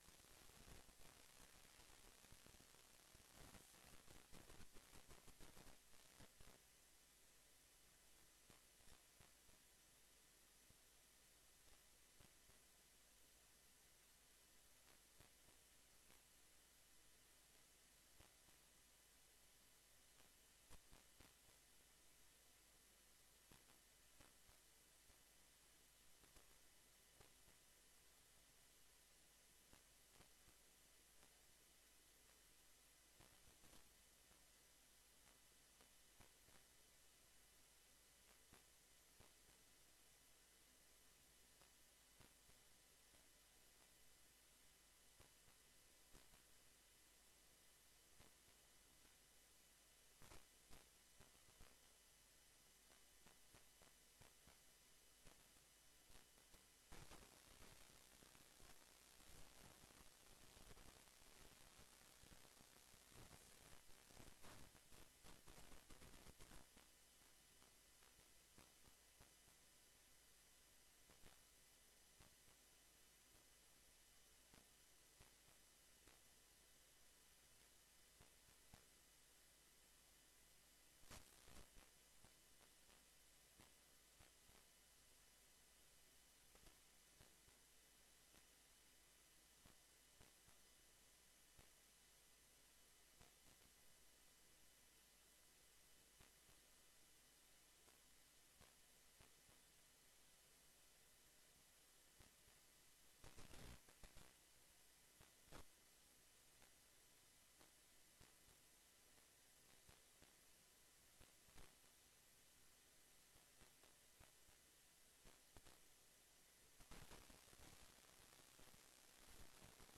VOORTZETTING VAN DE OPENBARE VERGADERING VAN DE RAAD VAN DE GEMEENTE WEERT VAN 25 JUNI OP 30 JUNI 2025.